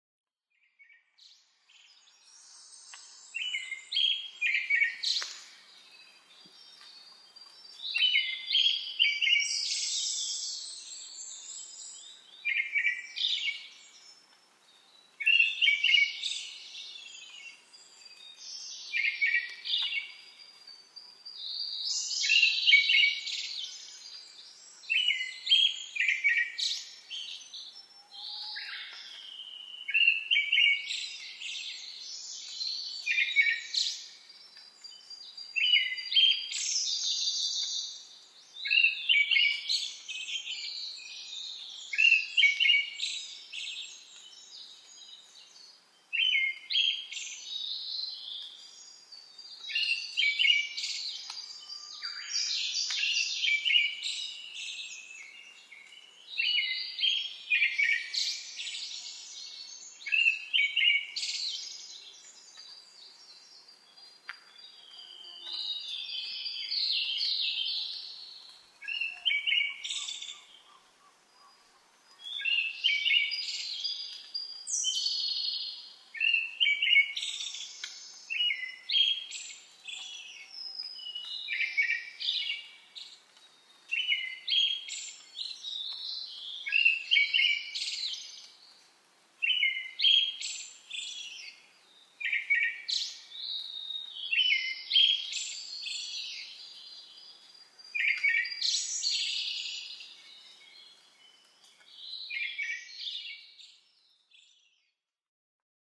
アカハラ　Turdus chrysolausツグミ科
日光市土呂部　alt=1210m
Mic: built-in Mic.
他の自然音：コルリ、ウグイス、